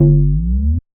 Bass_Down_Slide_G.wav